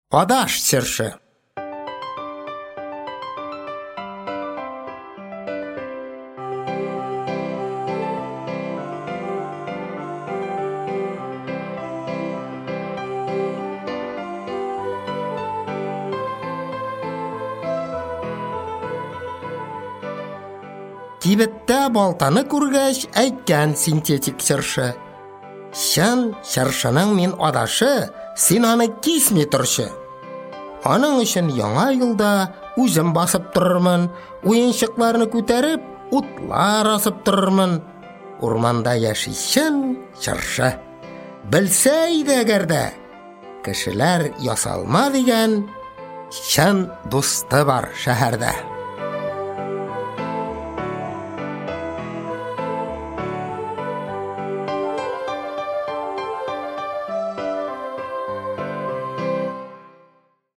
Аудиокнига Шигырьләр | Библиотека аудиокниг
Прослушать и бесплатно скачать фрагмент аудиокниги